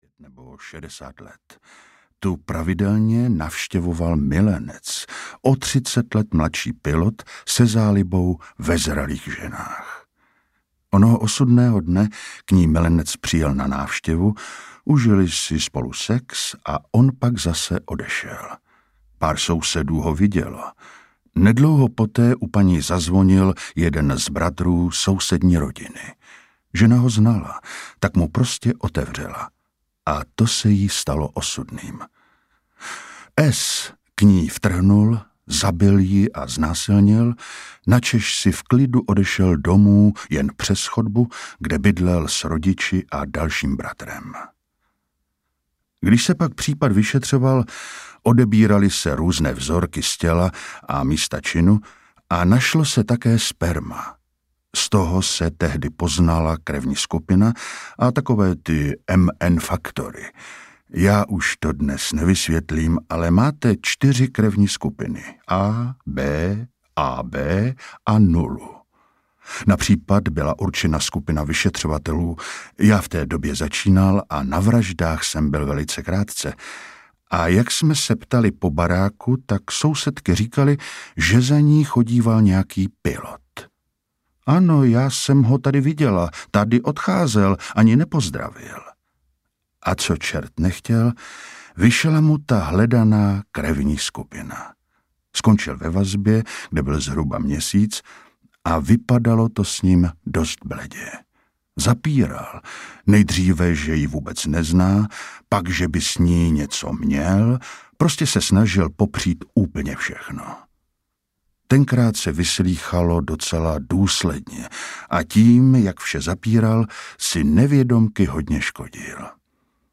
Vrah je v každém z nás audiokniha
Ukázka z knihy